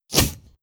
Close Combat Swing Sound 28.wav